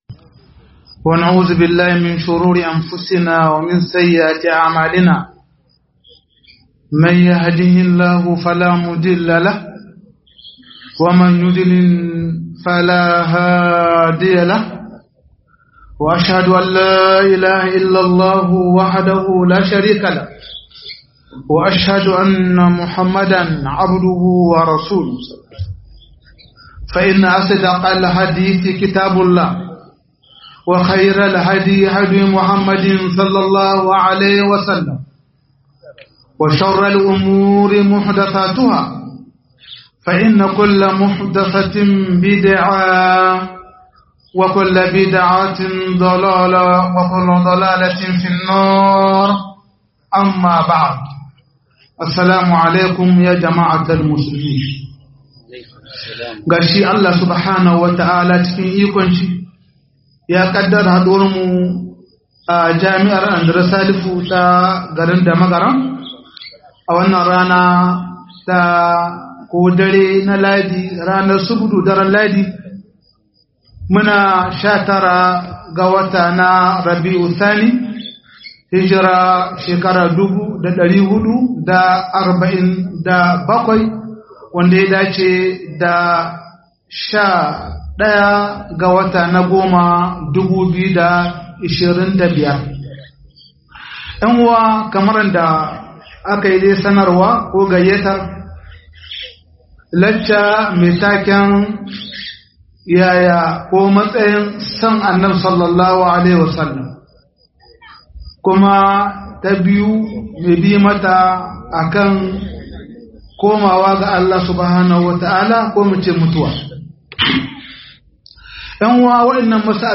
007 Suwaye Masoya Manzon Allah - MUHADARA